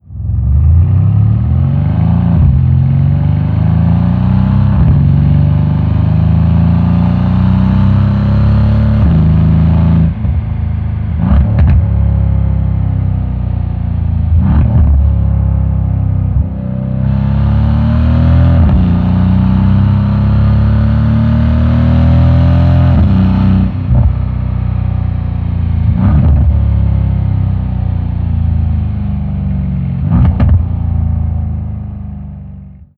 Auspuff Active Sound Einbausätze
Realistischer Klang: Erleben Sie echten Auspuffsound per Knopfdruck, ein- und ausschaltbar nach Belieben.